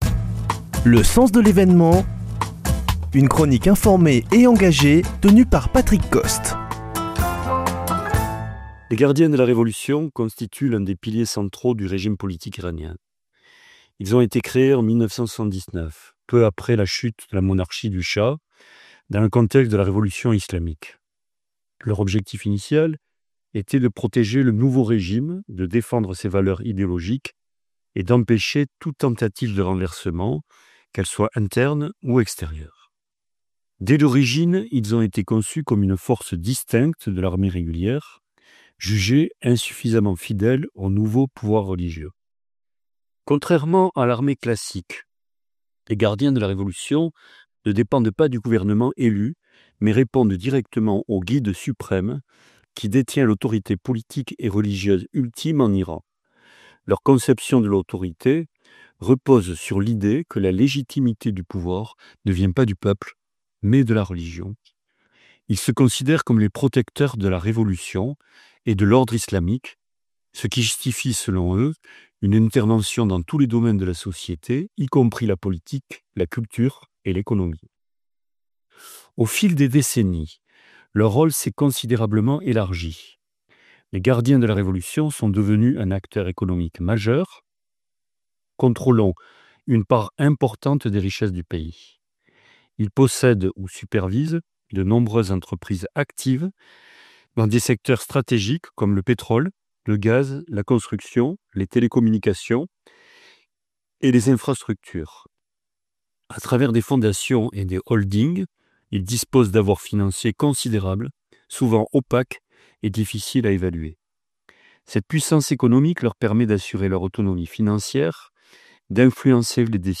Chroniqueur